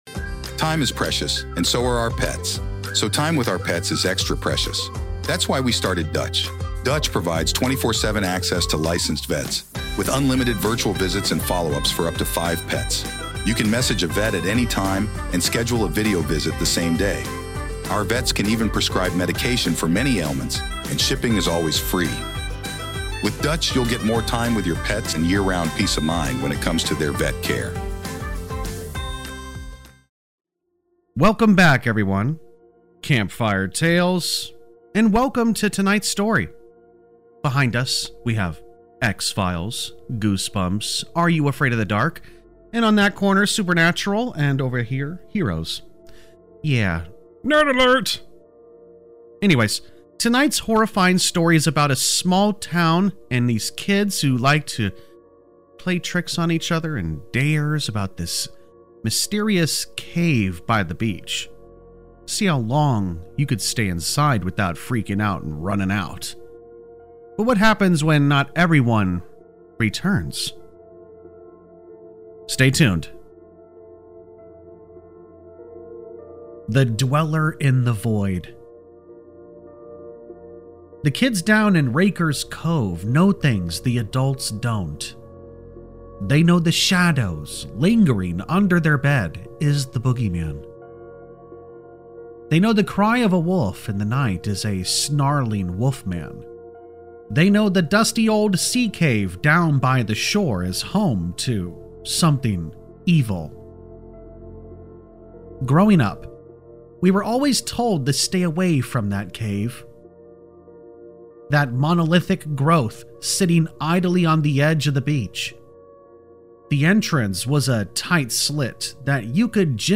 In tonight’s Creepypasta horror story, The Dweller In The Void, a group of kids in a quiet coastal town take part in a terrifying local dare—enter a dark beachfront cave and see who can last the longest without fleeing in fear.